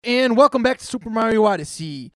What is this noise?
I encountered some distortion in the recording I did for one of my YouTube videos and can´t put my finger on what caused it.
I´m using a BEHRINGER XENYX Q802USB connected directly into my laptop, with a Shure SM58. You can hear the distortion right before I say "Odyssey".
Phantom power is on, gain is set to about half and everything else is at default.